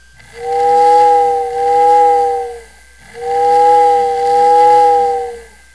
tin lithograph; whistles (
girard_whistle.wav